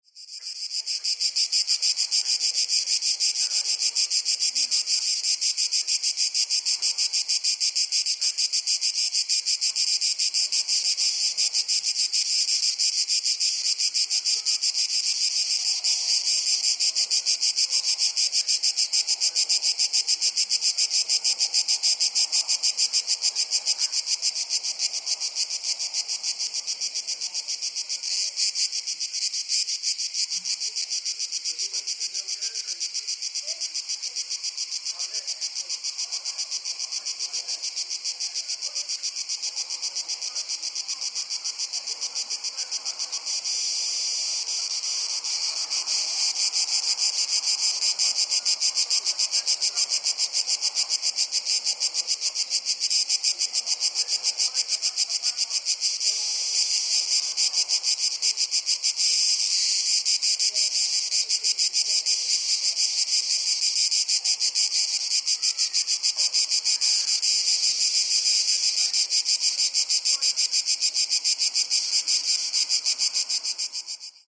Mediterranean-forest-summer-ambience-sound-effect.mp3